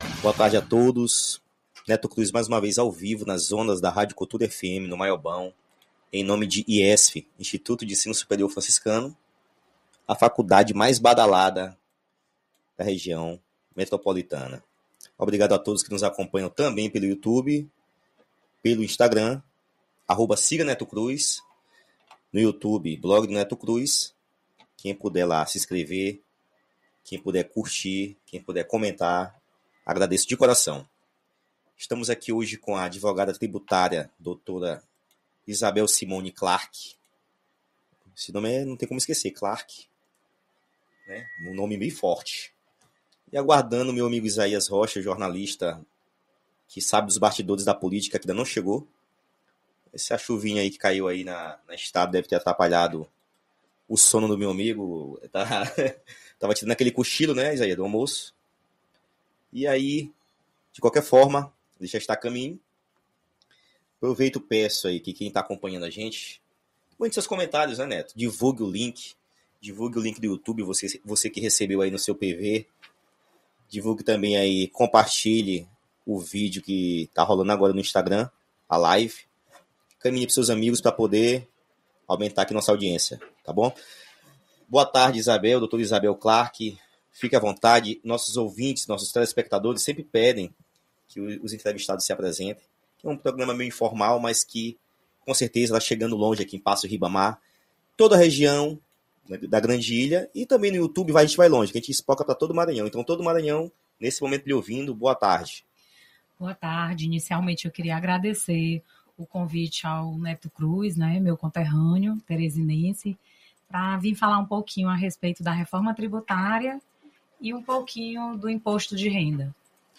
A entrevista com a advogada tributarista trouxe uma grande audiência ao programa com ouvintes participando, através de comentários ao vivo nas redes sociais e, por meio das demais plataformas, que a emissora disponibiliza para interação com o público.